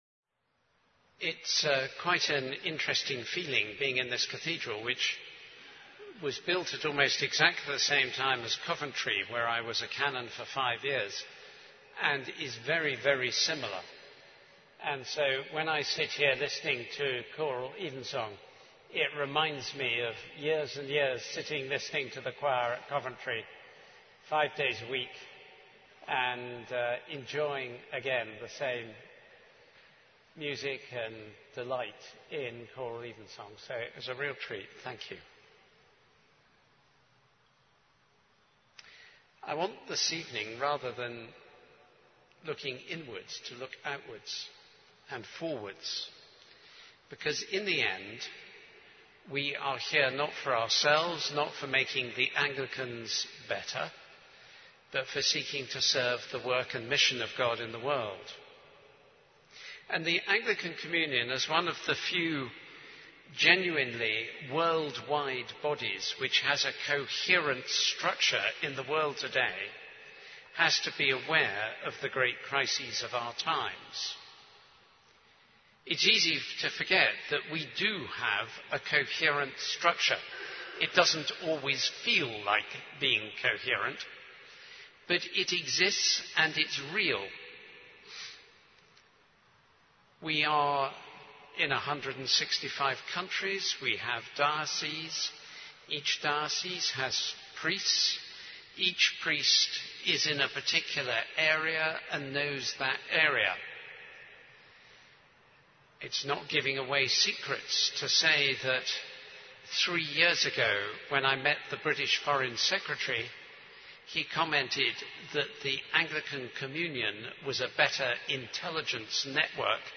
ACC-16 Presidential Address
acc16-presidential-address_small.mp3